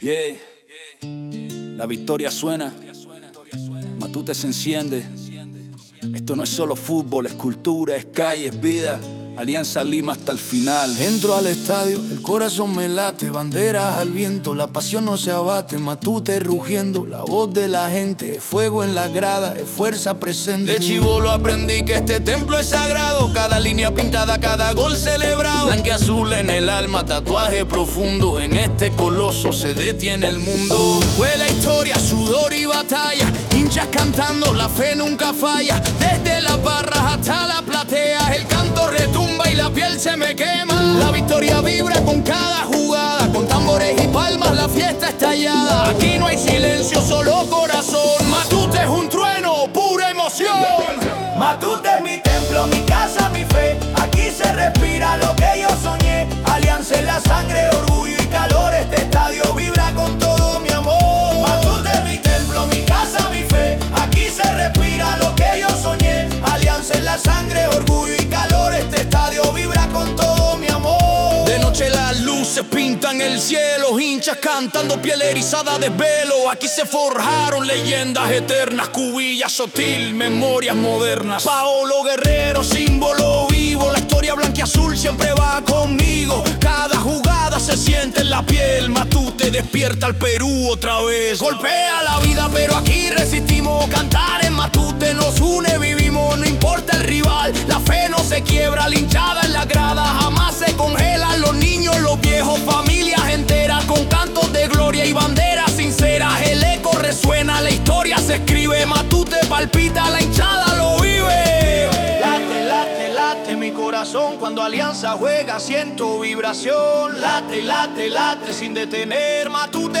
Himnos modernos inspirados en la historia, Matute y la pasión eterna del pueblo blanquiazul.
Rap dedicado al Estadio Alejandro Villanueva, Matute,